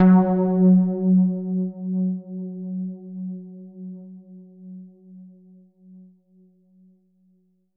SOUND  F#2.wav